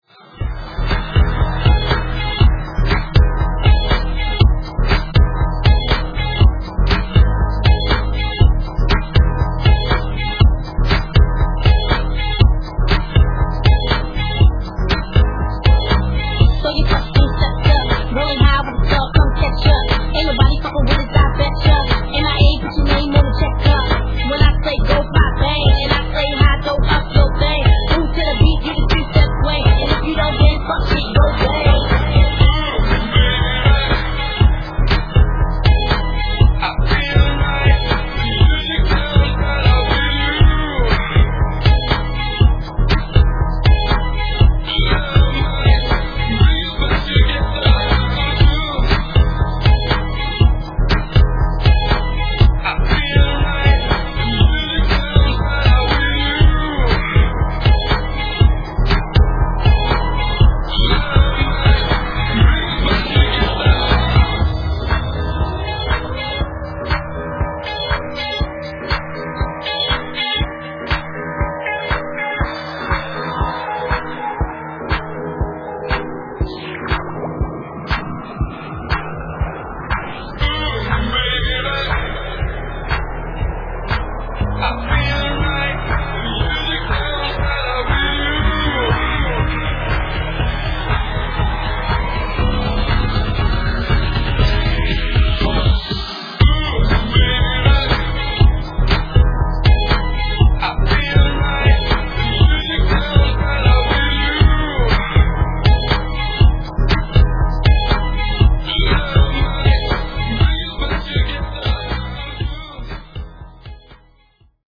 freshly remastered Dancefloor